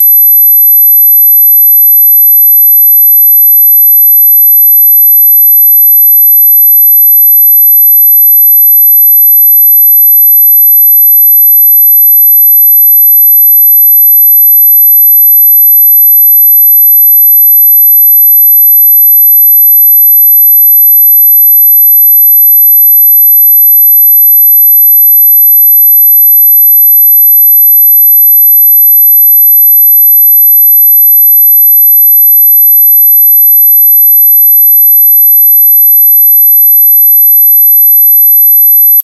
49歳以下の方も聞こえる音。
周波数12000Hz 着信音の試聴とダウンロード